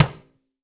Index of /90_sSampleCDs/AKAI S6000 CD-ROM - Volume 5/Cuba2/TIMBALES_2
F-TIMB LCL-S.WAV